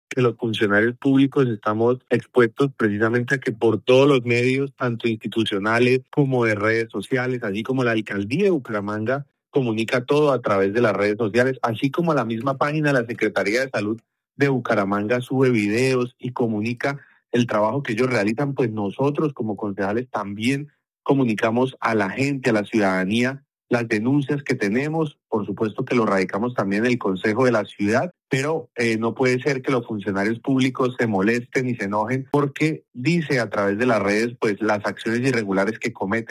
Camilo Machado, Concejal
La iniciativa fue presentada por el concejal Camilo Machado, quien explicó por qué considera necesaria esta medida y qué espera del debate: